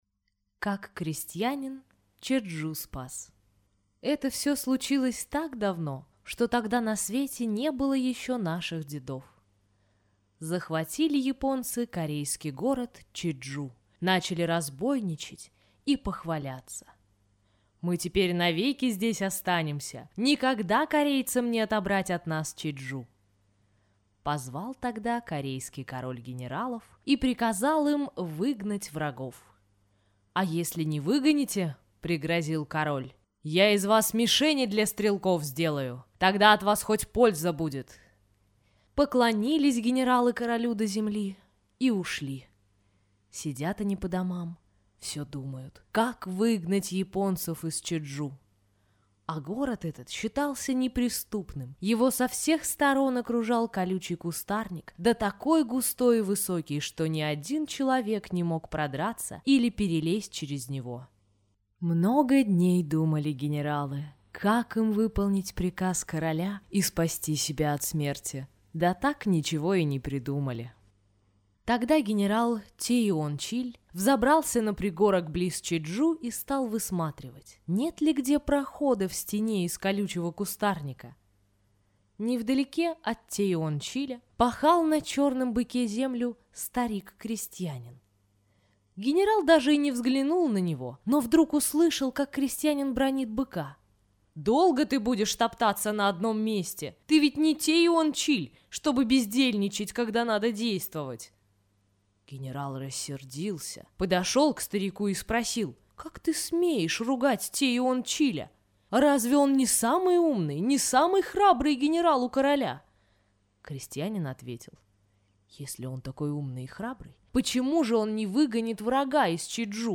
Как крестьянин Чечжу спас – корейская аудиосказка